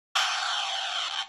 explosion.mp3